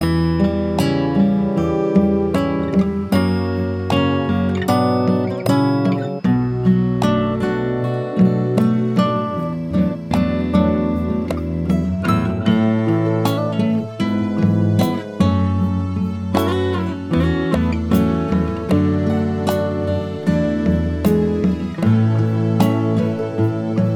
Minus Slide Guitars Soft Rock 4:30 Buy £1.50